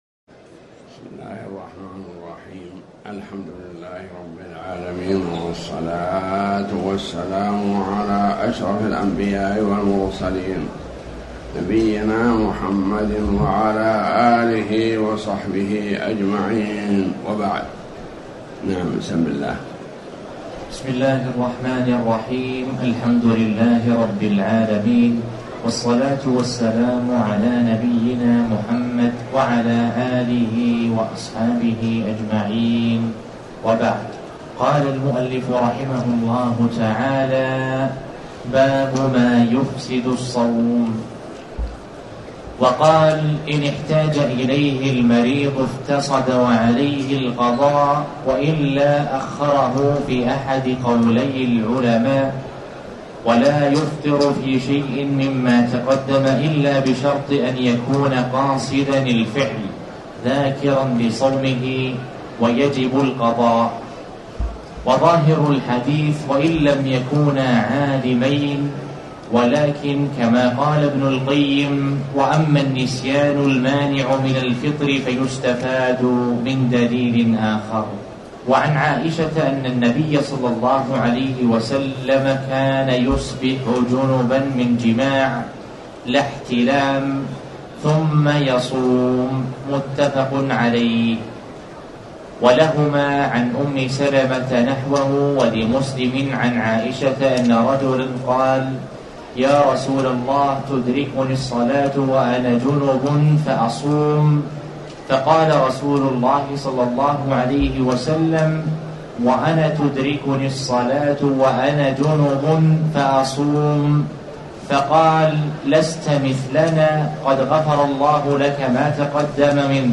تاريخ النشر ١٧ رمضان ١٤٣٩ هـ المكان: المسجد الحرام الشيخ